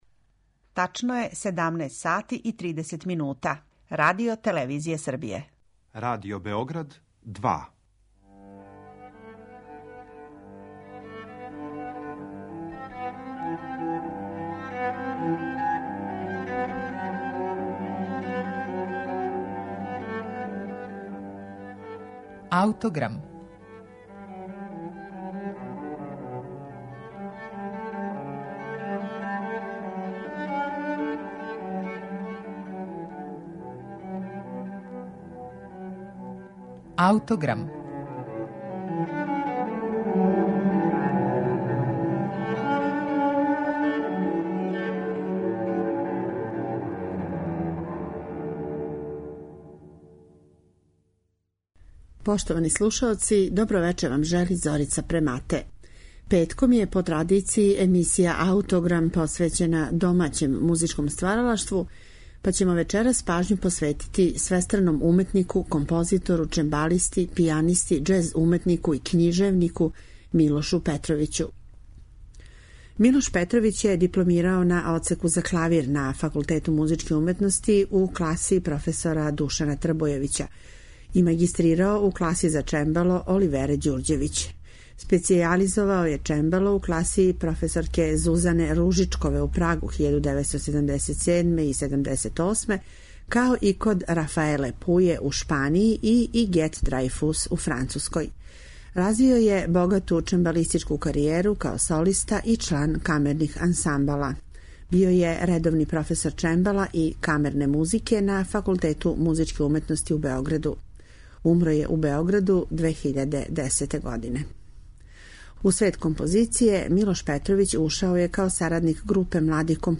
за чембало